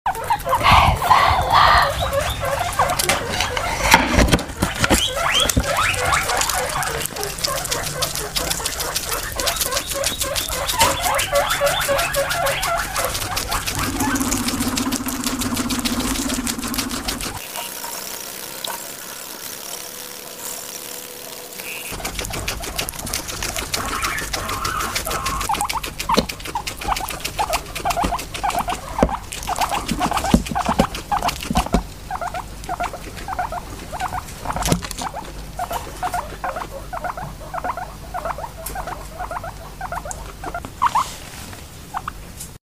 奇怪，今天的電報機怎麼一直響？